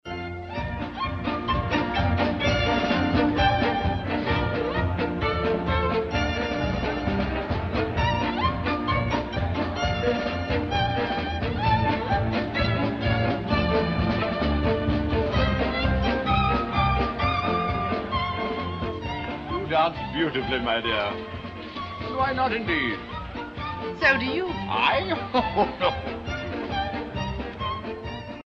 这里我把从电影里截取的舞曲片段给各位大侠作为参考。